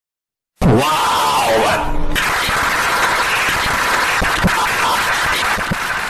Wow Clap Fast